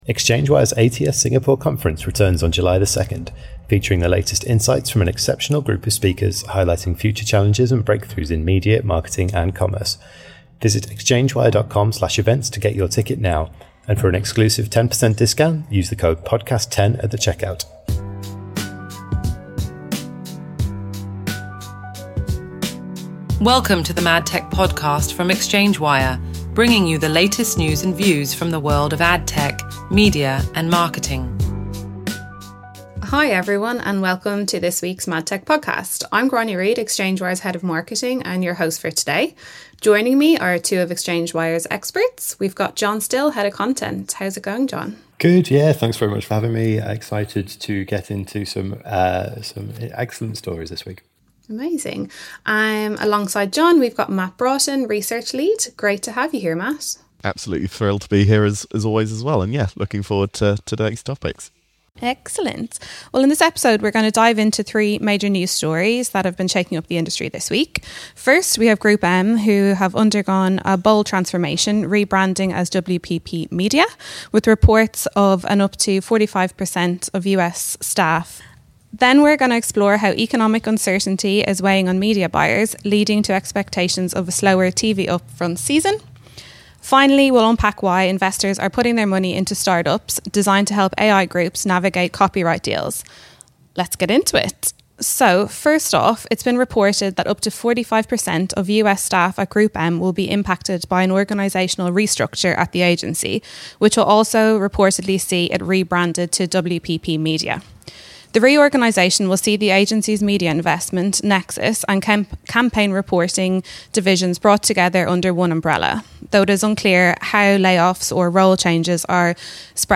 team discussion